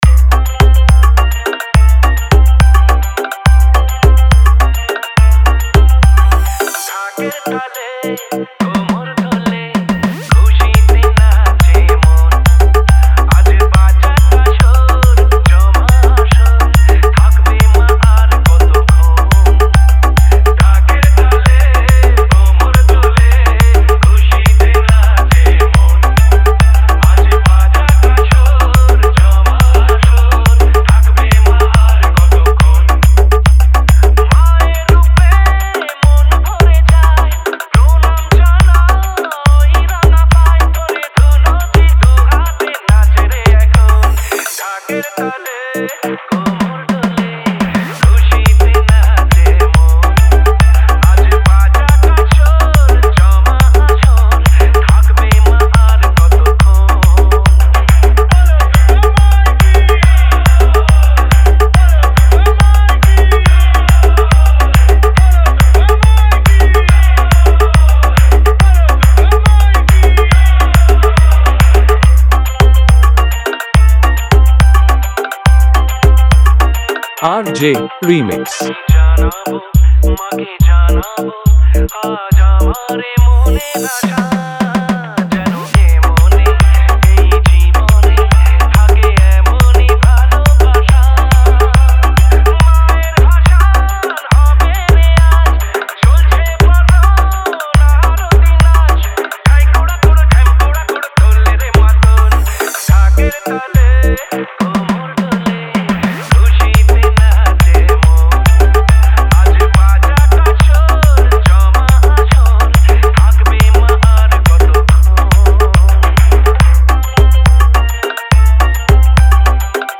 দুর্গ উৎসব 2024 স্পেশাল হামবিং ভক্তি বাংলা গান